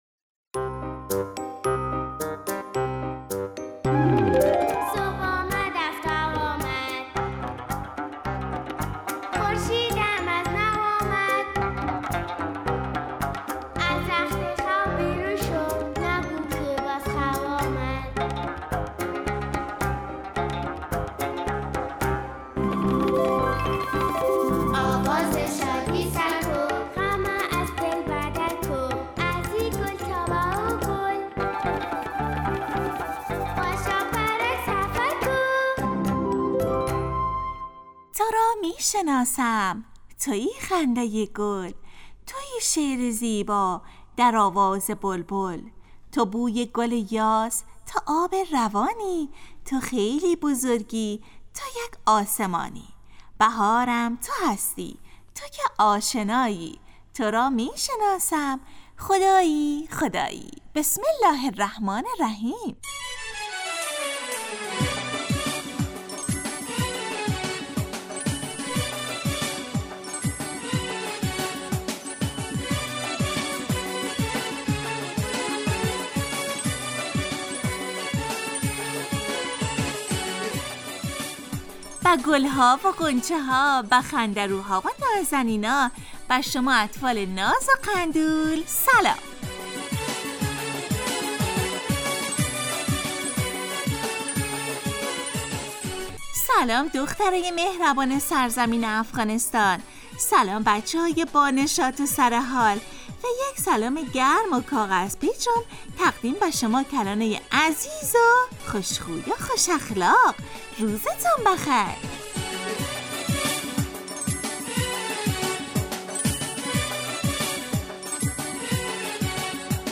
شاپرک برنامه ای ترکیبی نمایشی است که برای کودکان تهیه و آماده میشود.این برنامه هرروز به مدت 15 دقیقه با یک موضوع مناسب کودکان در ساعت 8:45 صبح به وقت افغانستان از رادیو دری پخش می گردد.